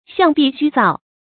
向壁虛造 注音： ㄒㄧㄤˋ ㄅㄧˋ ㄒㄩ ㄗㄠˋ 讀音讀法： 意思解釋： 向壁：臉朝著墻壁；虛造：弄虛作假。